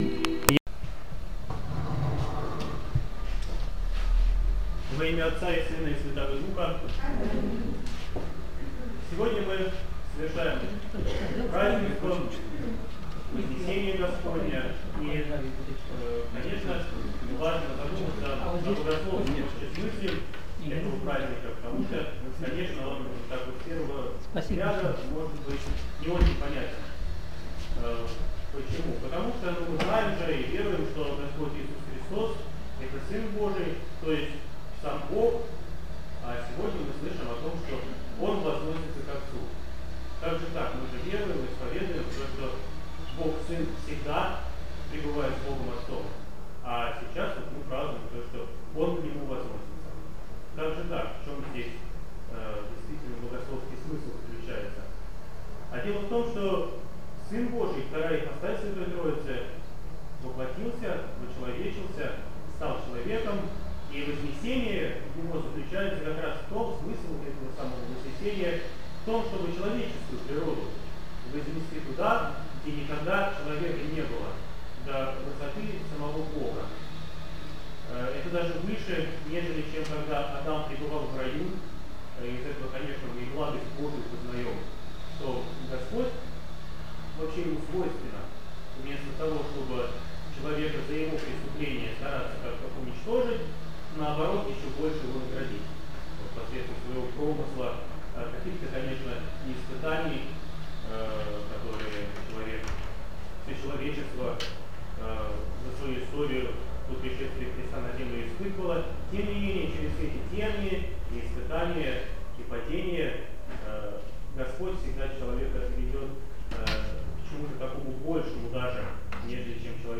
Проповедь, Вознесение 2013